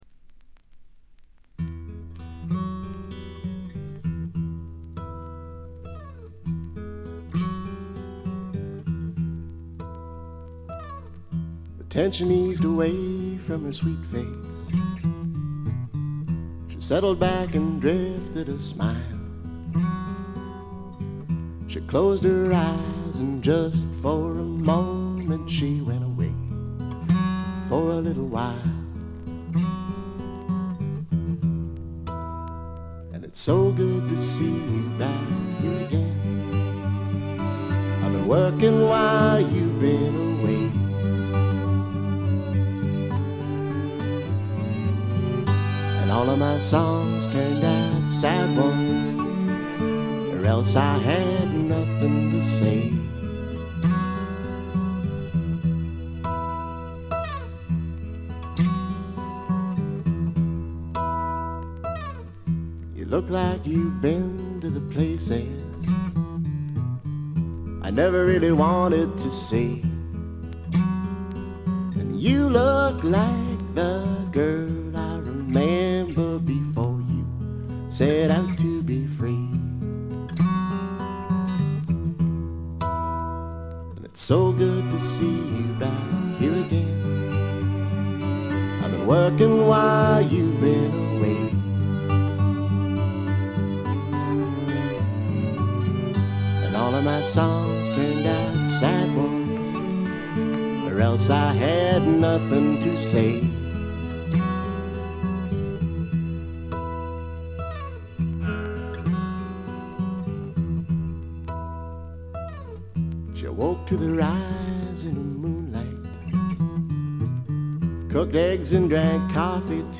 ごくわずかなノイズ感のみ。
カナディアン・シンガー・ソングライターの名盤。
試聴曲は現品からの取り込み音源です。
acoustic guitar